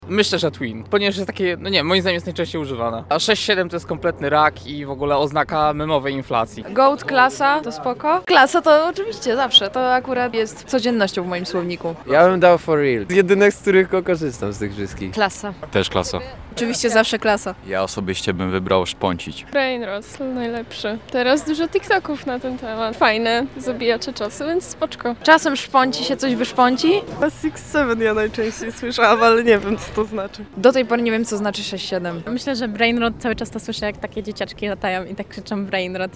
Zapytaliśmy studentów o personalny wybór zwycięzcy.